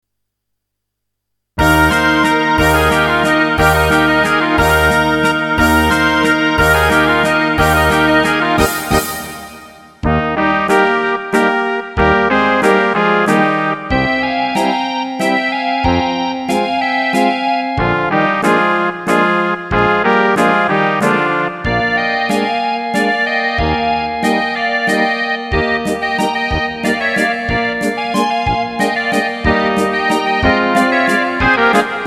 Rubrika: Národní, lidové, dechovka
Česká lidová
Karaoke